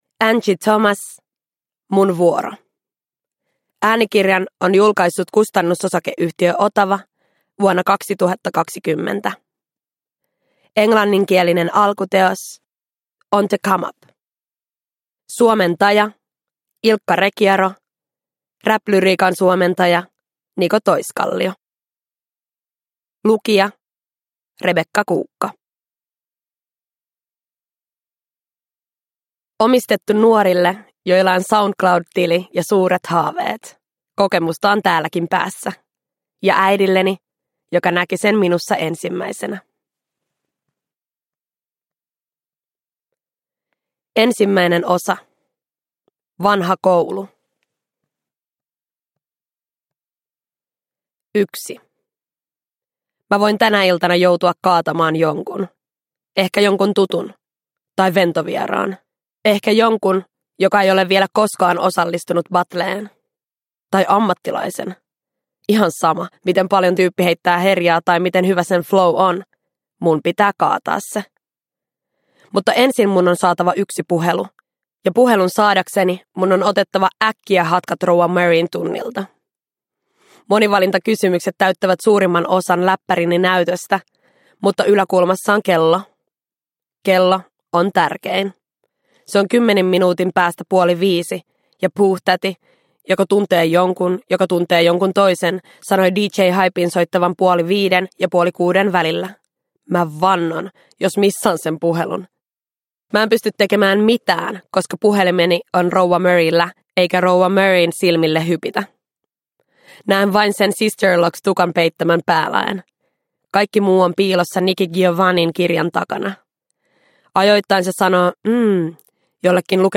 Mun vuoro – Ljudbok – Laddas ner